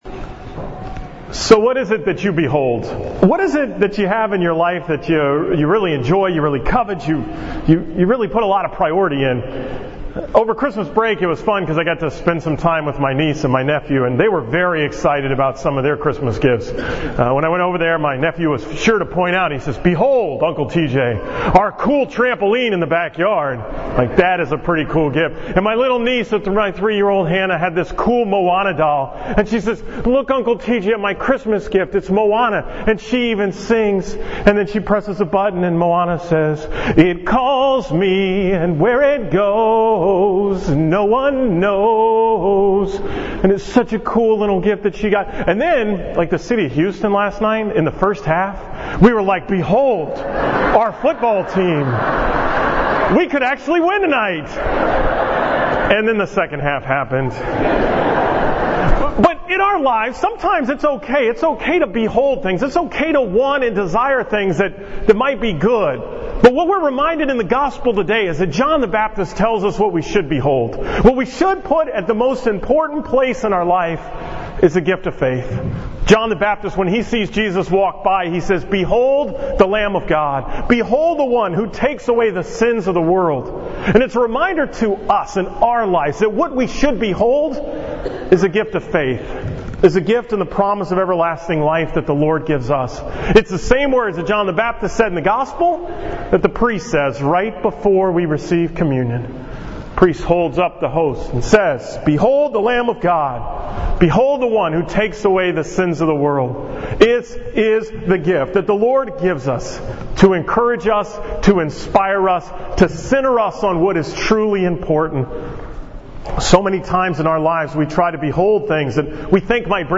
Homily from 11 am Mass at St. Laurence on January 15, 2017